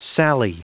Prononciation du mot sally en anglais (fichier audio)
Prononciation du mot : sally